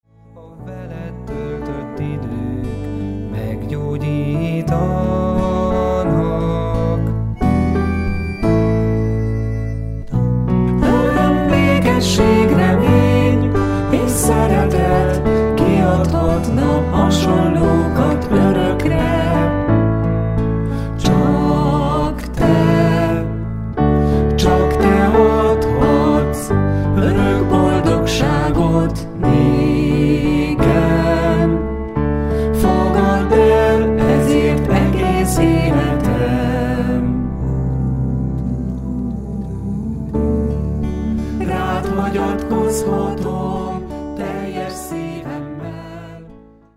ének és próza
gitár
zongora
basszusgitár